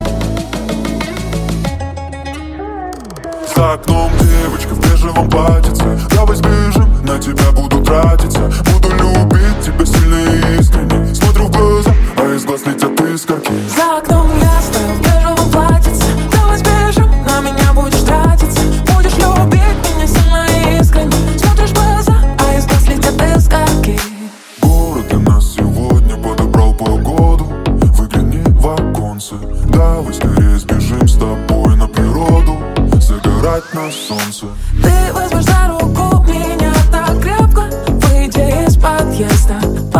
Танцевальные рингтоны / Романтические рингтоны
Клубные рингтоны